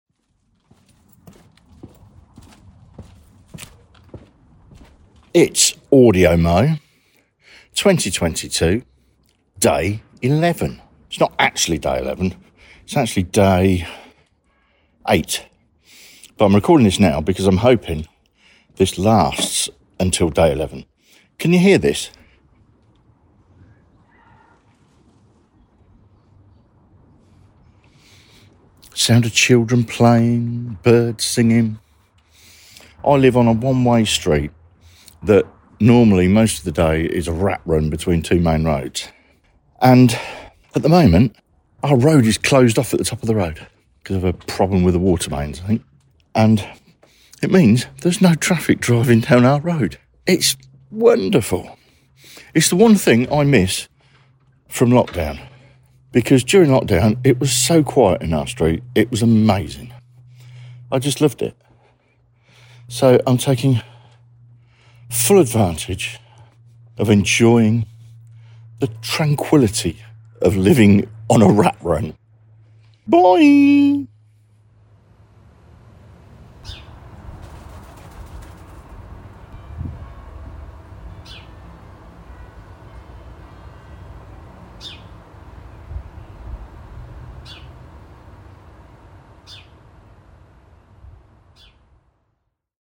I'm enjoying the silence ... well, near silence.